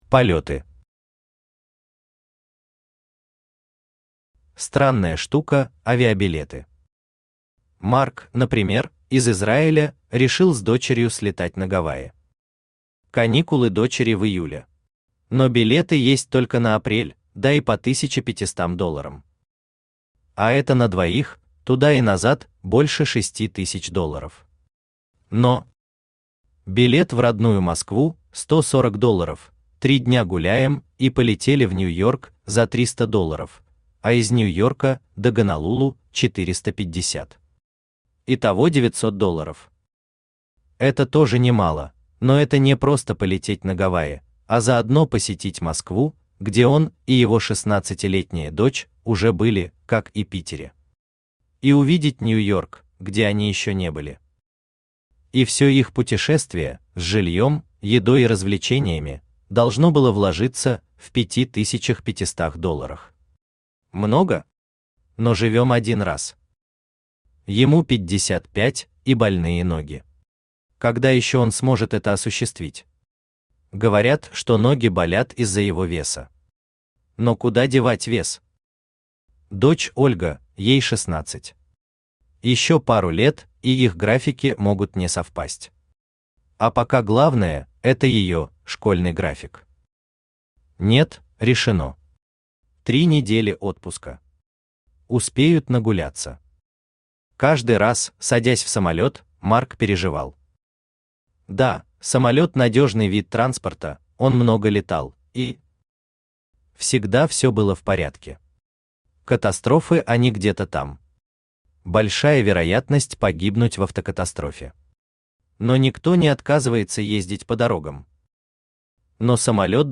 Aудиокнига Остров Краба Автор Сергей Юрьевич Ростовцев Читает аудиокнигу Авточтец ЛитРес.